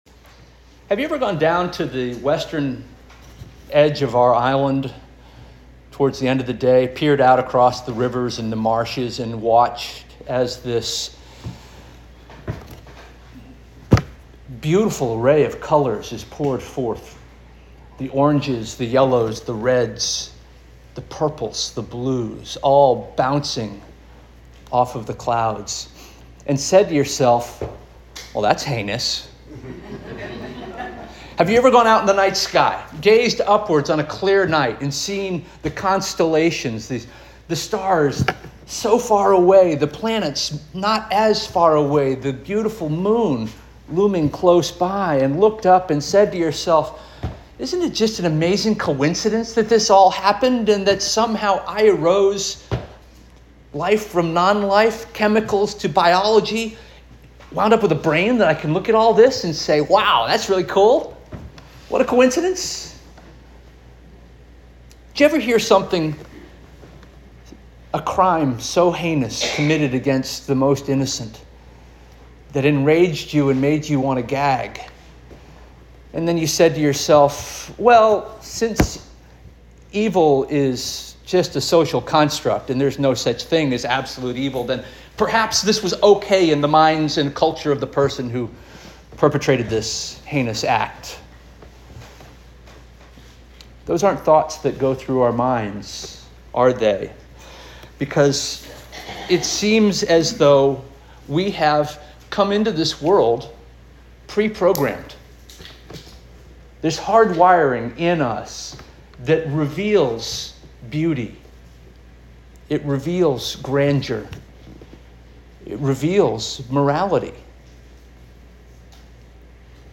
September 28 2025 Sermon - First Union African Baptist Church